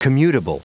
Prononciation du mot commutable en anglais (fichier audio)
Prononciation du mot : commutable